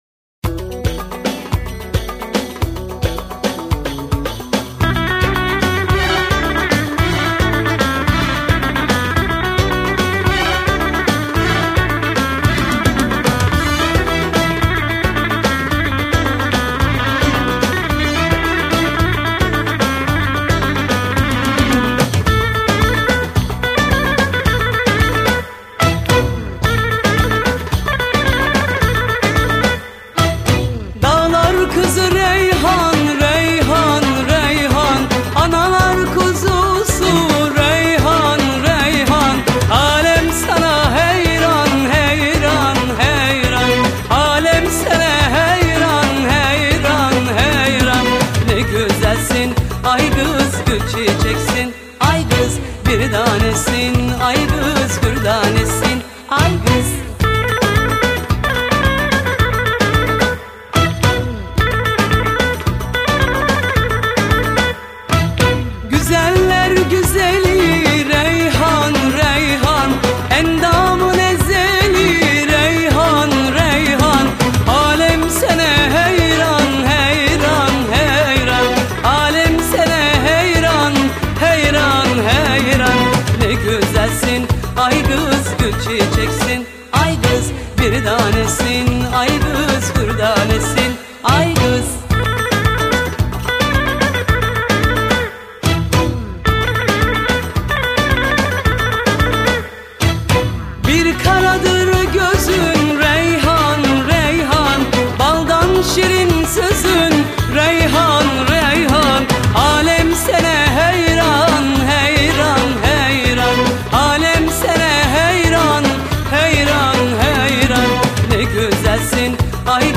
خواننده زن